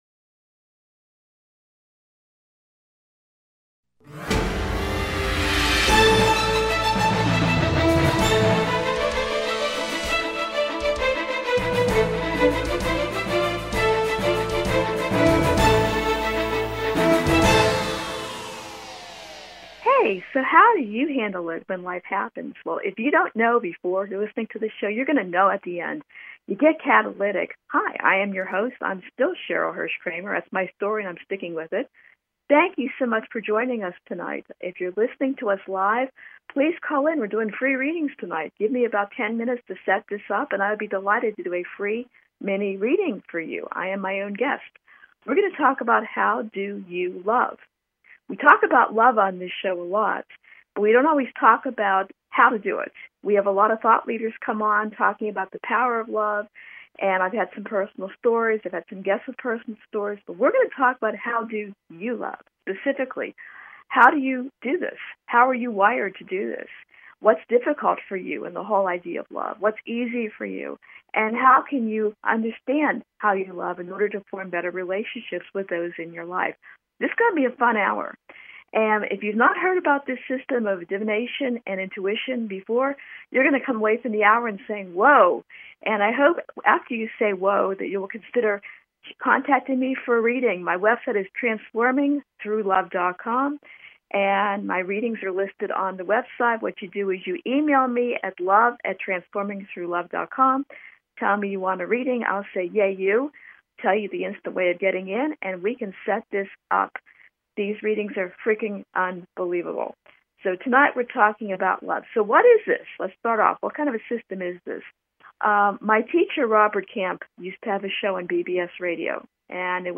Talk Show Episode, Audio Podcast, Catalytic and How do YOU love? on , show guests , about Loving,how do you love,cultivating relationships,divination system,learning to love, categorized as Self Help,Spiritual,Variety